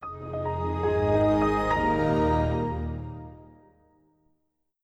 Many people think the Windows XP startup sound is the most memorable. Made by Bill Brown, this sound was meant to make you feel safe and steady.
Windows XP Startup Sound
Windows_XP.wav